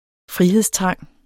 Udtale [ ˈfʁiheðsˌtʁɑŋˀ ]